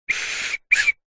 Звуки свиста и свистков
8. Звук свиста сломанного свистка:
zvuk-svista-slomannogo-svistka.mp3